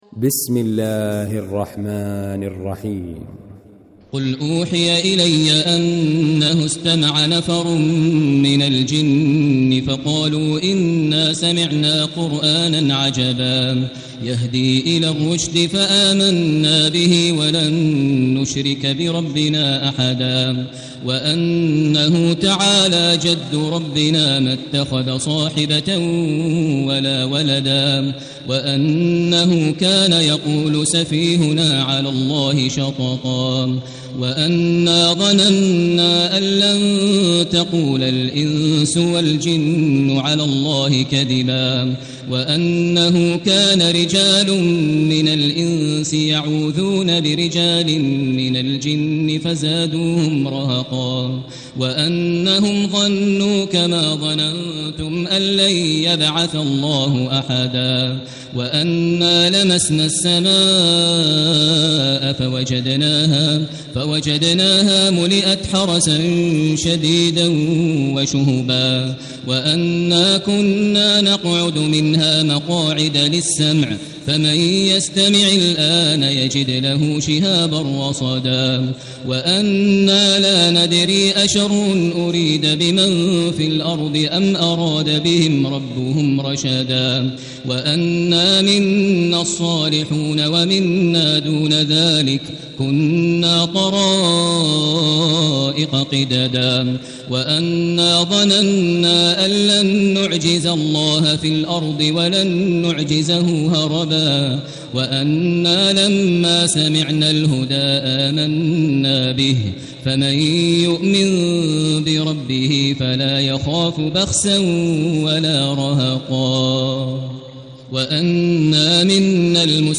المكان: المسجد الحرام الشيخ: فضيلة الشيخ ماهر المعيقلي فضيلة الشيخ ماهر المعيقلي الجن